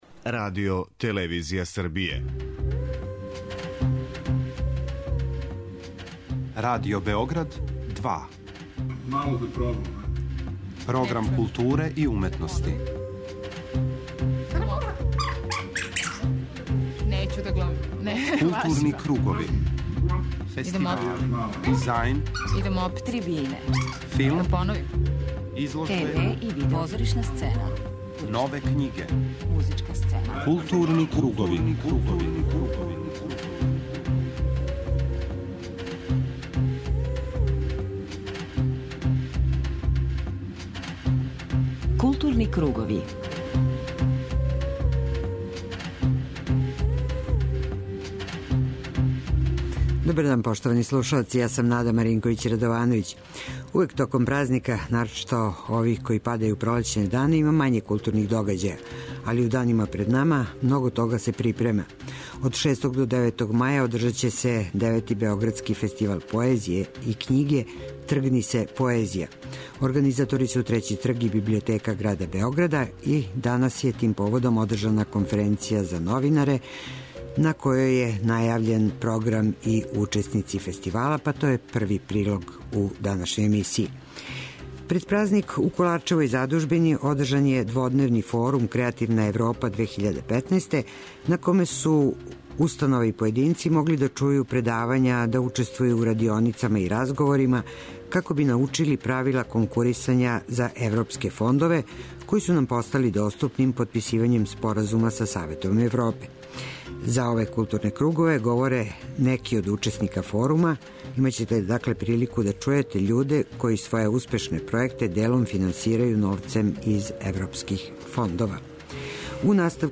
У првом сату емисије информисаћемо вас о актуелним културним догађајима, а затим следи блок посвећен музици.